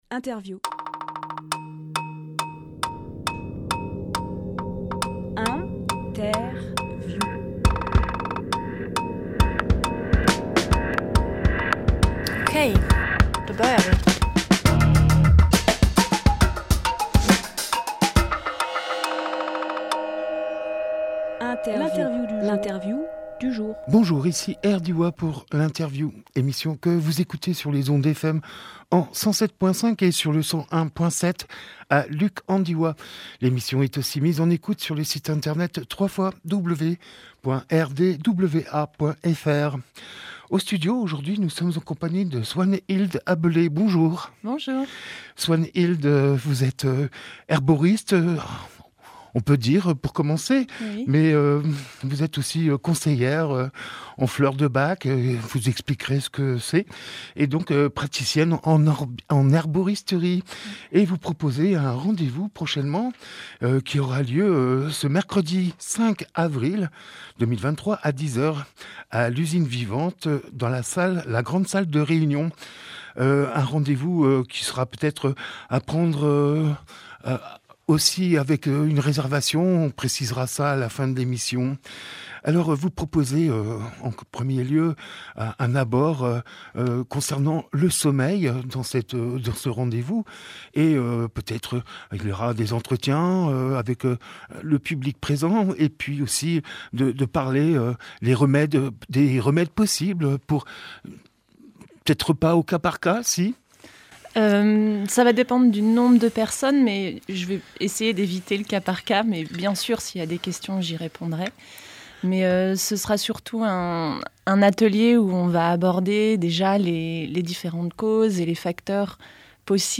Emission - Interview
31.03.23 Lieu : Studio RDWA Durée